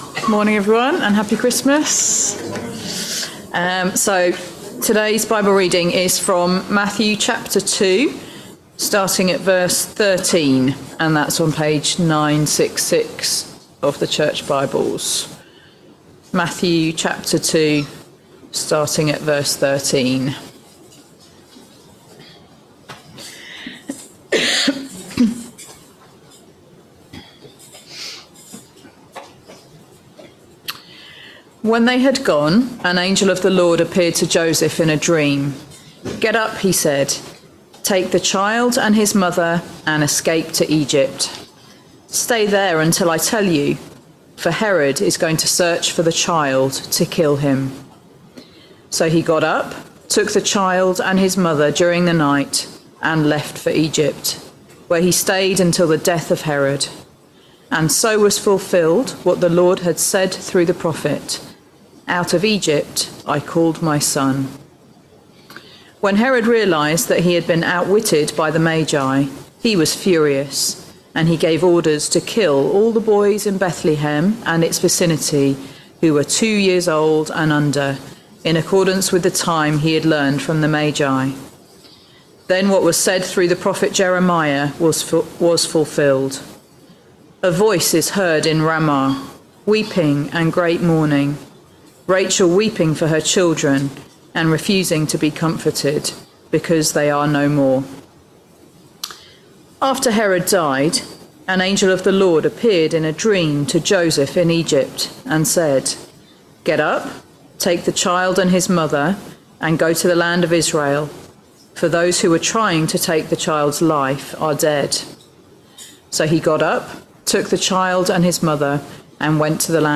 Sunday Morning All Age Service Topics